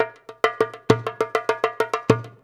100DJEMB25.wav